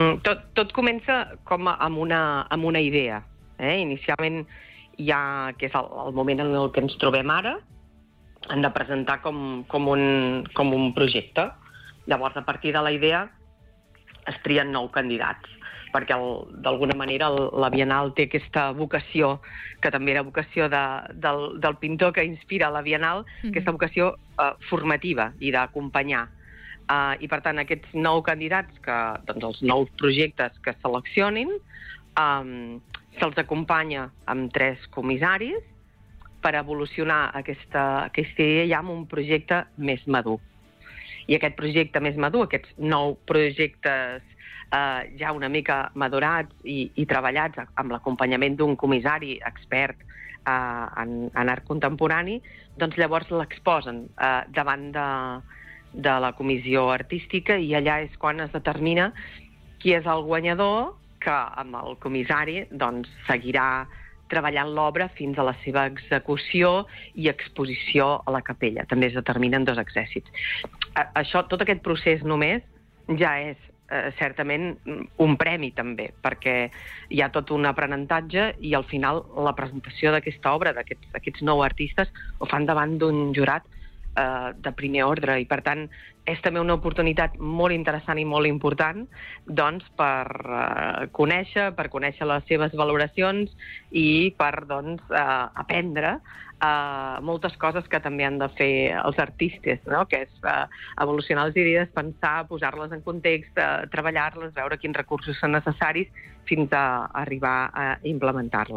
Entrevistes